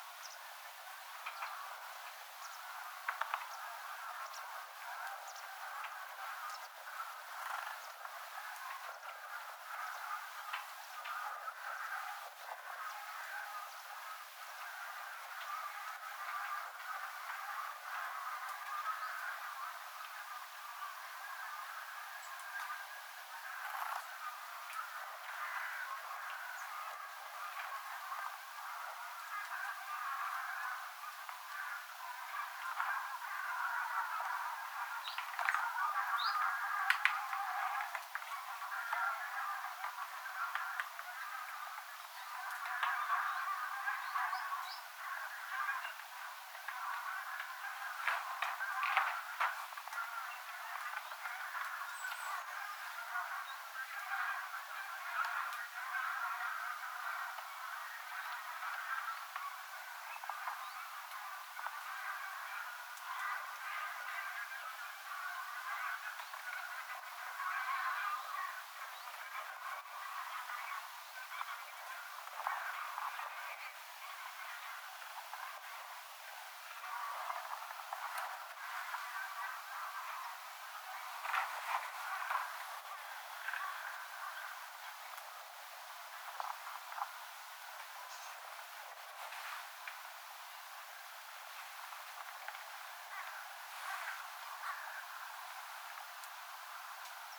yllättäen jostain ilmestyi metsähanhiparvi rannan ylle
metsahanhiparvi_rannan_ylla.mp3